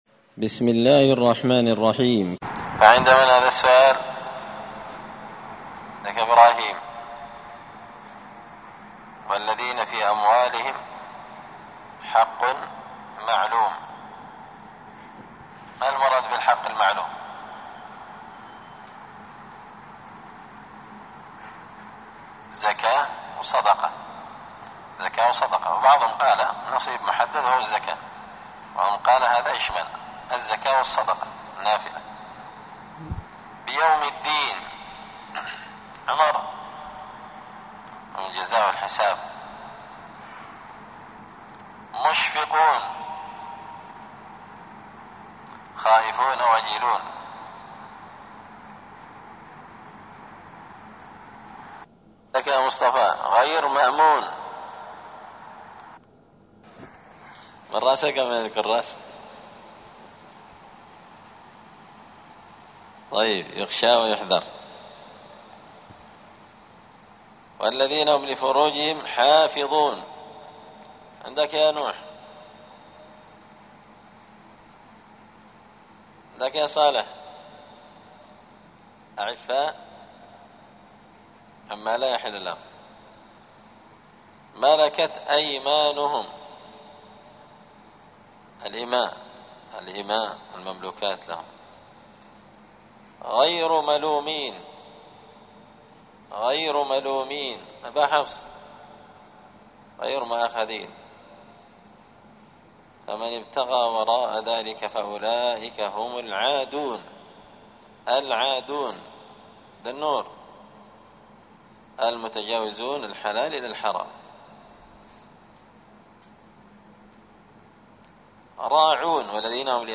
مسجد الفرقان قشن_المهرة_اليمن